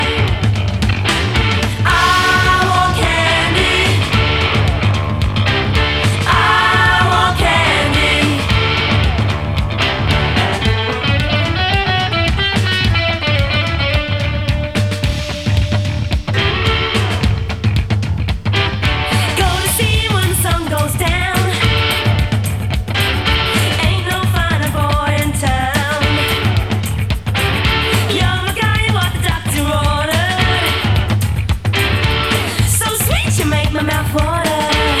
Жанр: Поп / Рок / Альтернатива